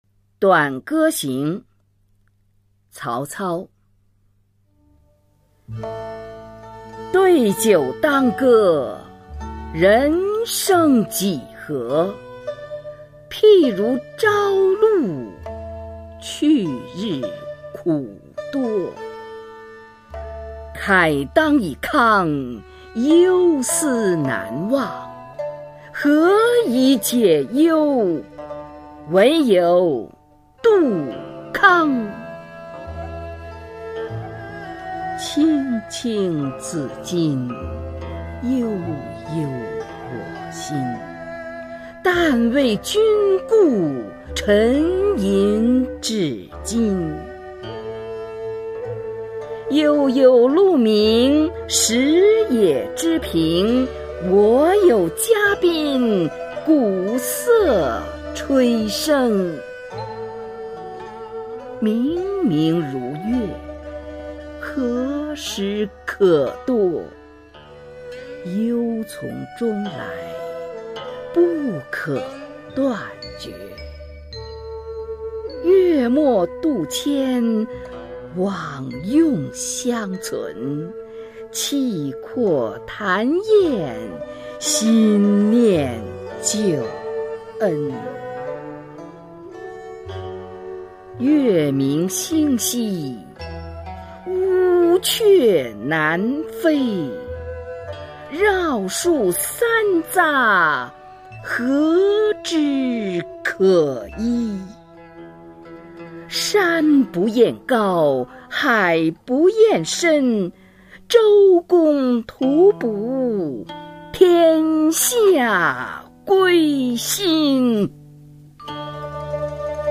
[魏晋诗词诵读]曹操-短歌行（女） 古诗朗诵